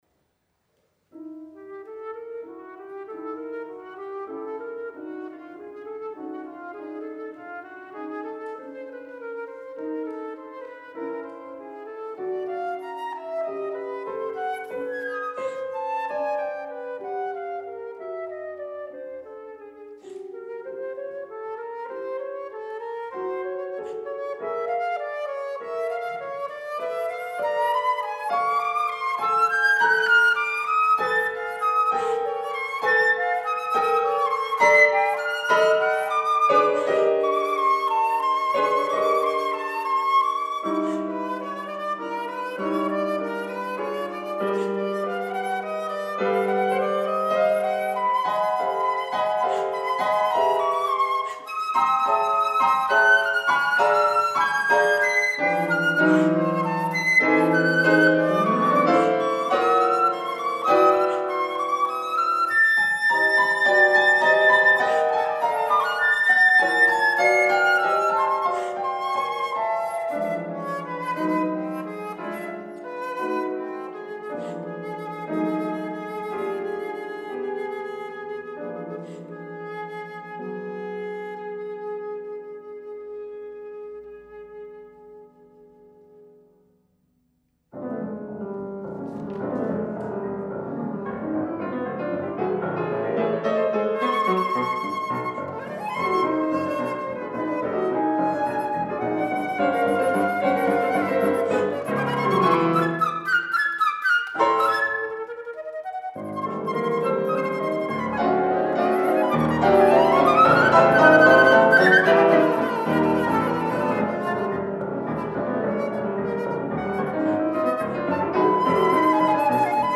5. Internationaler Wettbewerb 2003